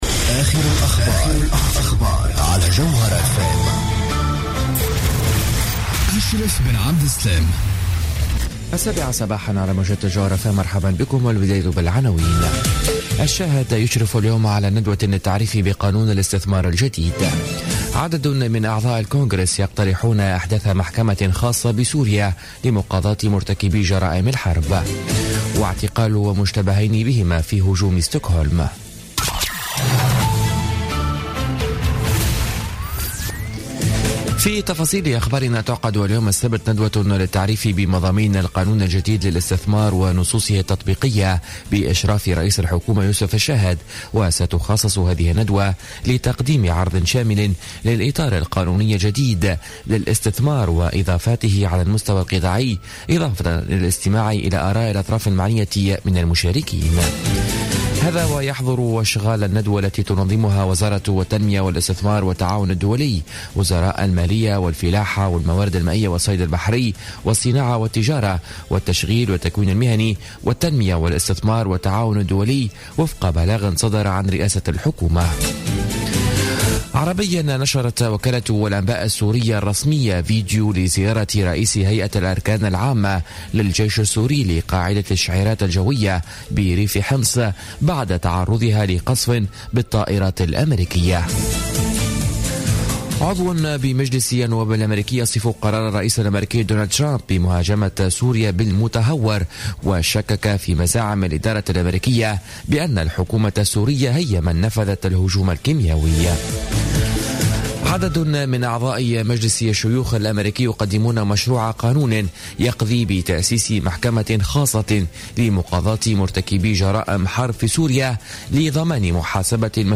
نشرة أخبار السابعة صباحا ليوم السبت 8 أفريل 2017